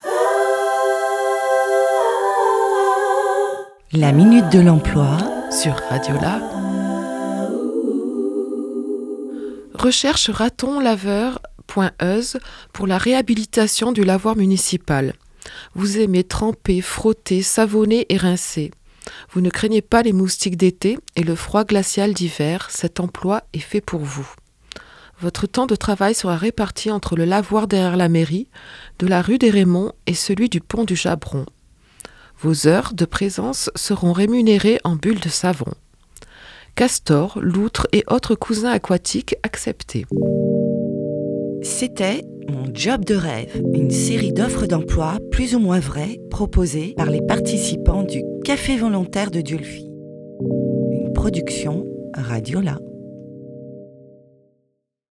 « Mon job de rêve » est une série d’offres d’emploi décalées, écrite et enregistrée par des participant-es du Café volontaire de Dieulefit en juillet 2024.